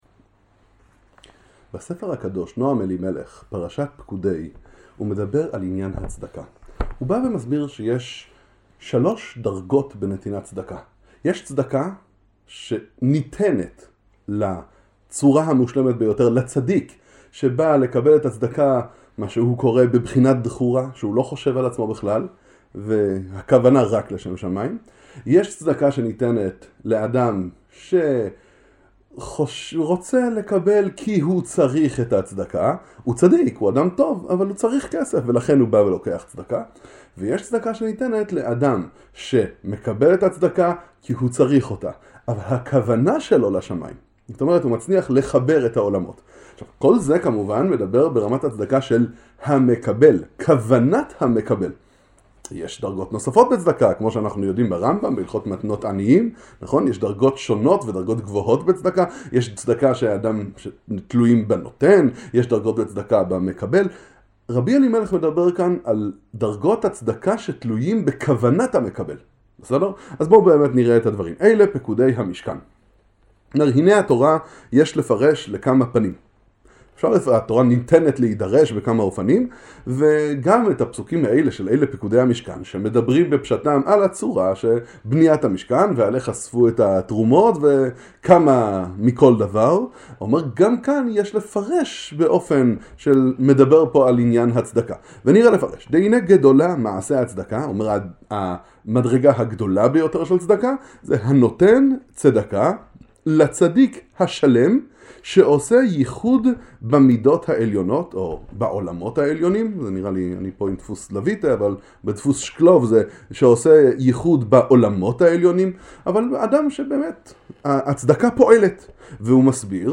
מהי עבודת מקבל הצדקה? שיעור בספר הקדוש נועם אלימלך פרשת פקודי - ג' צדיקים מקבלי צדקה